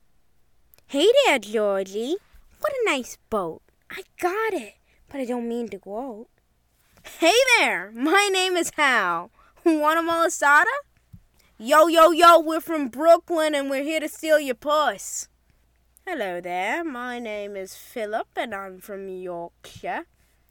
Animation
Teen-Animation-VO.mp3